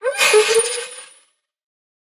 Minecraft Version Minecraft Version 25w18a Latest Release | Latest Snapshot 25w18a / assets / minecraft / sounds / mob / allay / idle_with_item1.ogg Compare With Compare With Latest Release | Latest Snapshot